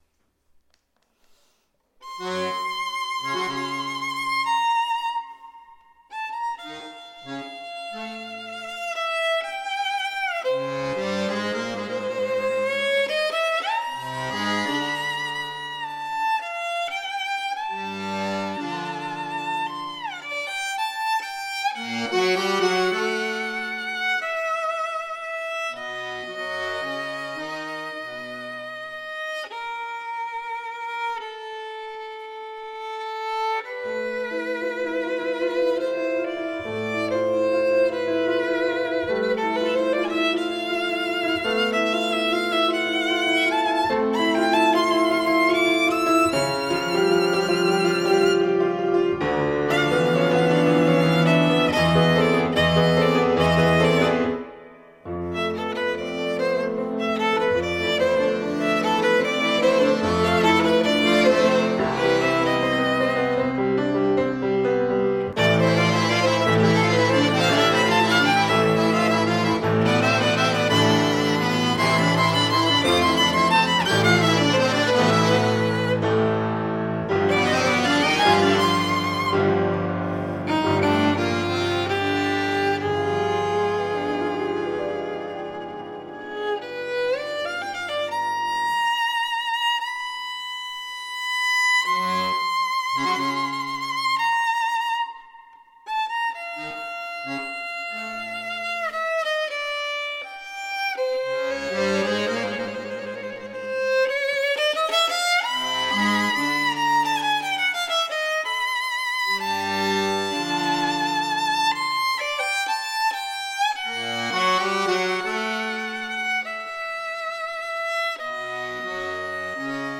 Violino, Fisarmonica e Pianoforte